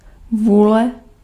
Ääntäminen
IPA: /ˈvɪ̀lja/